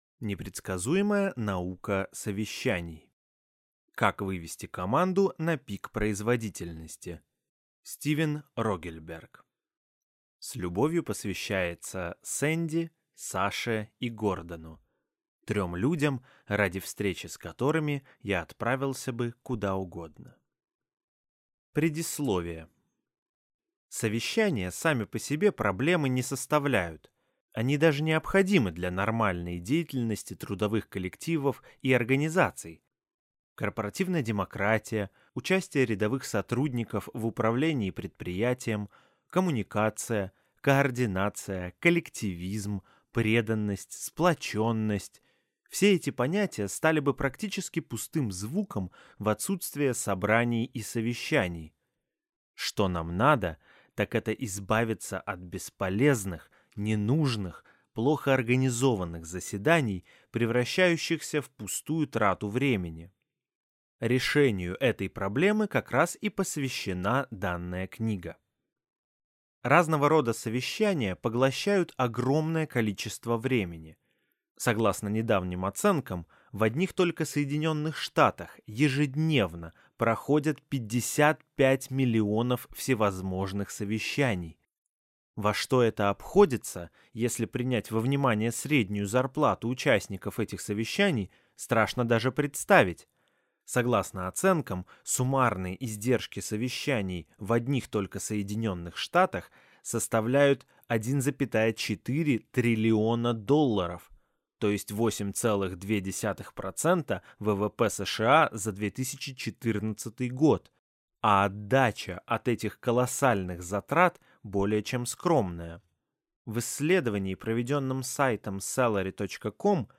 Аудиокнига Непредсказуемая наука совещаний. Как вывести команду на пик производительности | Библиотека аудиокниг